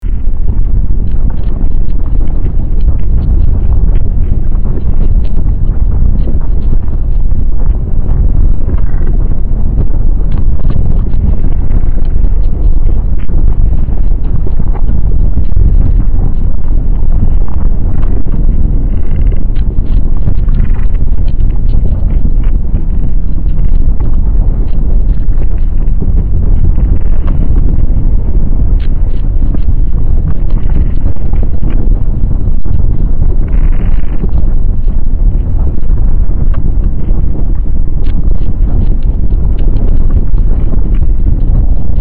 earth-rumble-6953.wav